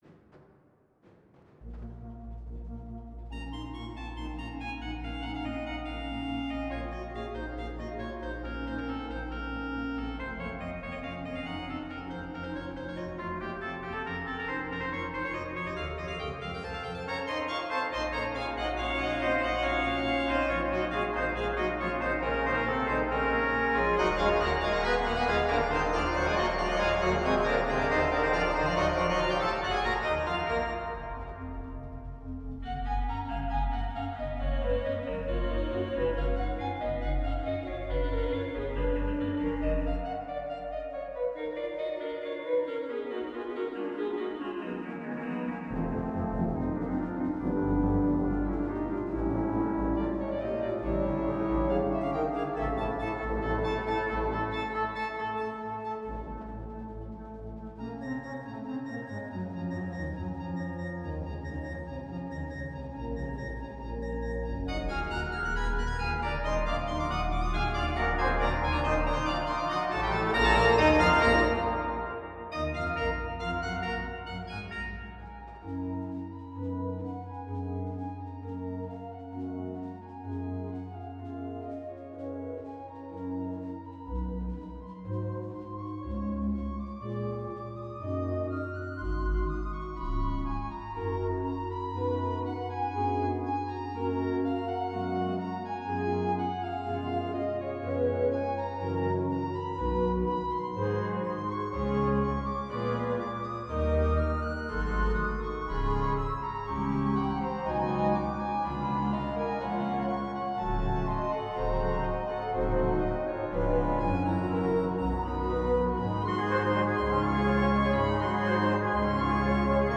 All audio examples were recorded with a selection of factory presets, unless stated otherwise (customized).